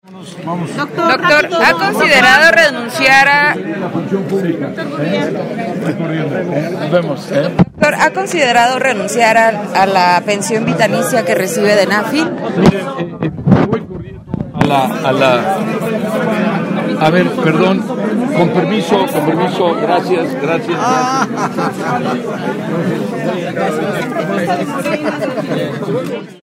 Al preguntarle si ha considerado renunciar a esta prestación durante la entrevista banquetera, Gurría no sólo evadió responder, sino que se abrió paso entre los reporteros y, prácticamente, salió corriendo.